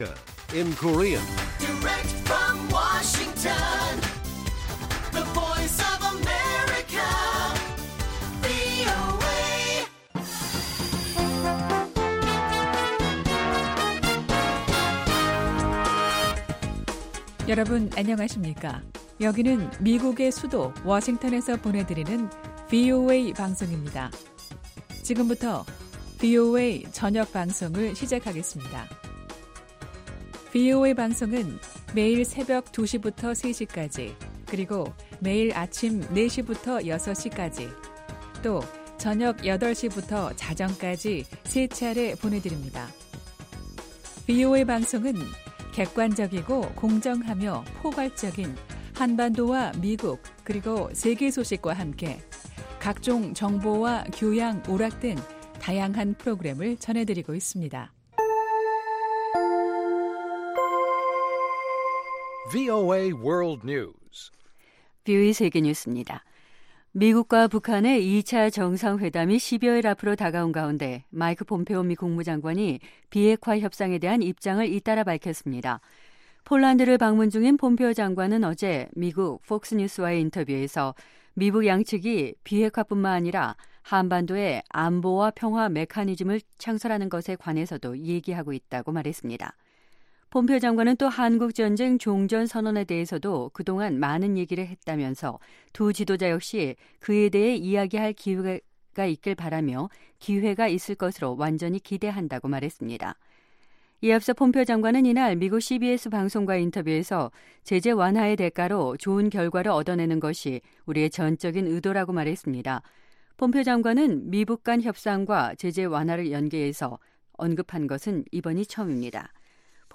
VOA 한국어 간판 뉴스 프로그램 '뉴스 투데이', 2019년 2월 15일 1부 방송입니다. 폼페오 미 국무장관은 미국 언론과의 인터뷰에서 북한의 비핵화는 검증할 수 있는 방법으로 이뤄져야 한다고 말했습니다. 미 국무부는 대북 인도적 지원 승인 요청을 면밀히 검토할 것이라면서 미국이 직접 지원할 계획은 없다고 밝혔습니다.